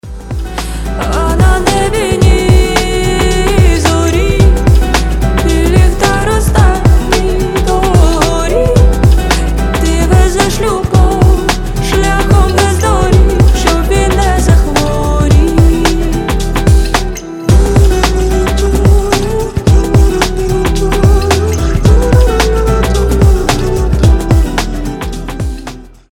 • Качество: 320, Stereo
красивые
женский вокал